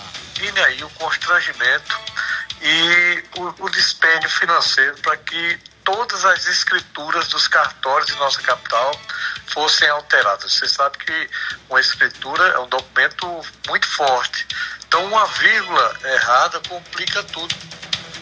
Em entrevista ao programa Arapuan Verdade, da Rádio Arapuan FM desta quarta-feira (18/12), Hervázio expressou complicações caso o nome da Capital fosse alterado.